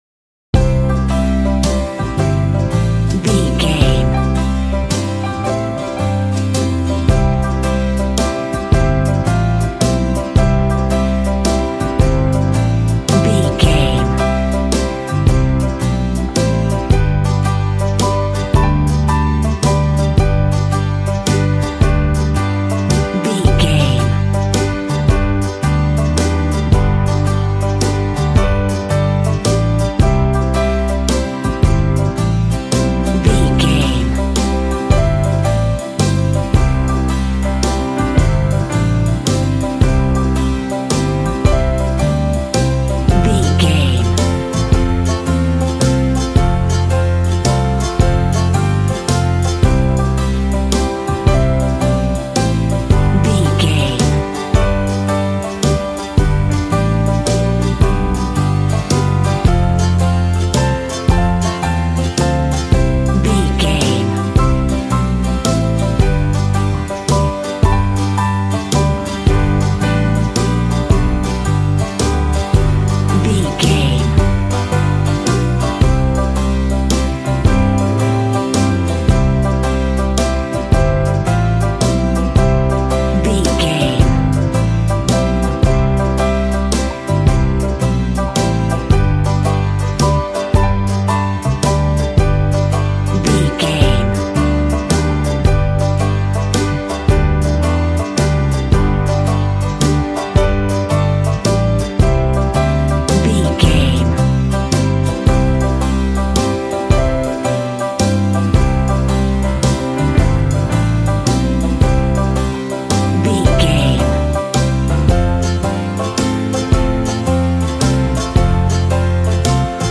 Ionian/Major
Fast
fun
bouncy
positive
double bass
drums
acoustic guitar